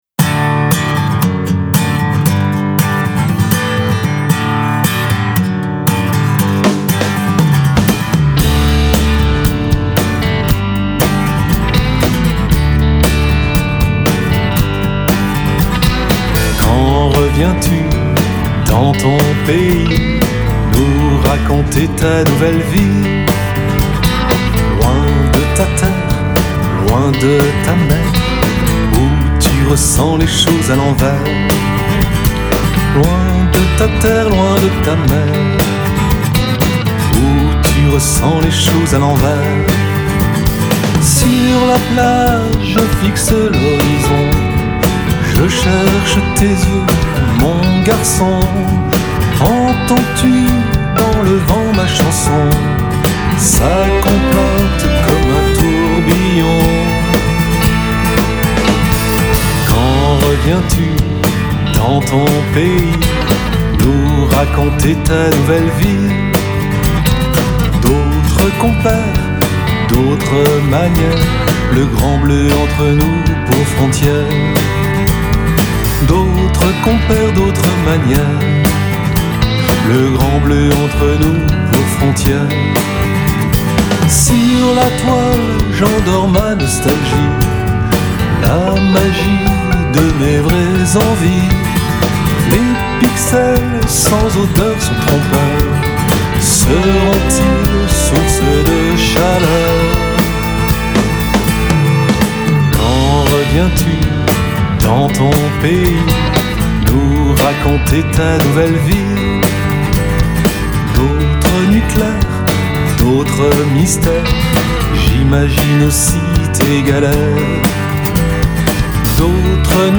Guitares
Basse
Piano
Batterie
Violon